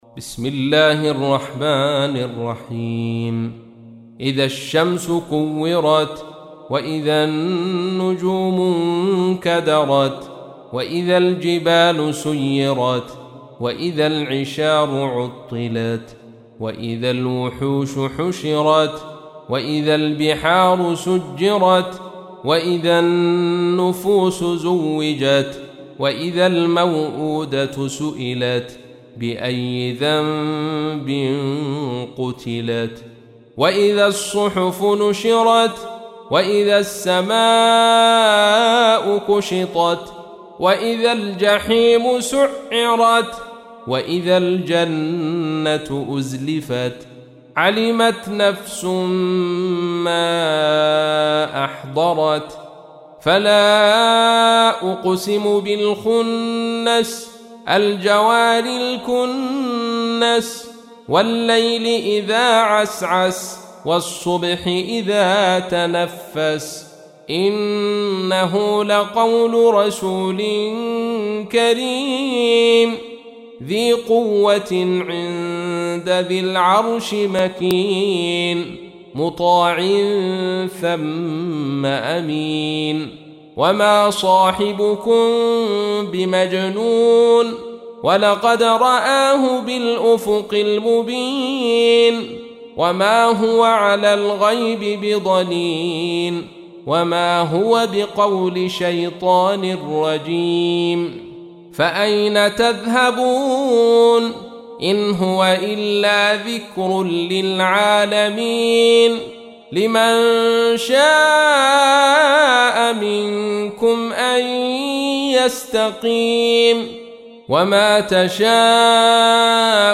تحميل : 81. سورة التكوير / القارئ عبد الرشيد صوفي / القرآن الكريم / موقع يا حسين